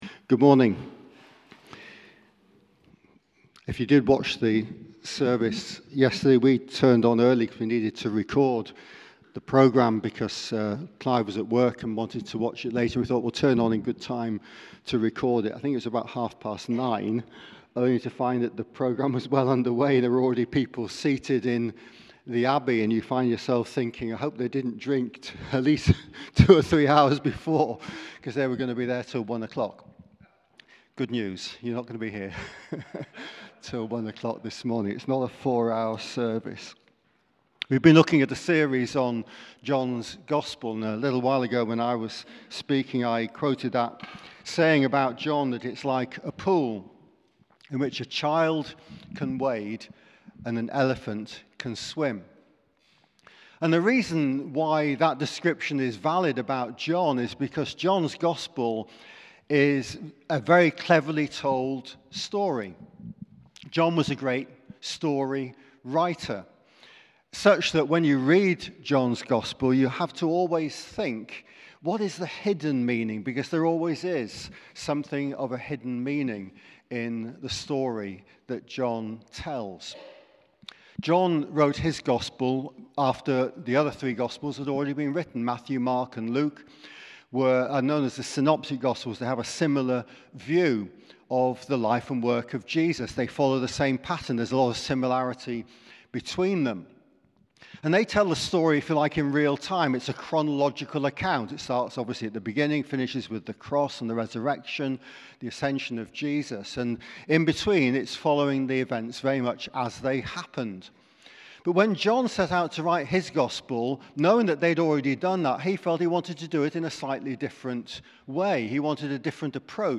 Sermon - John 3:1-20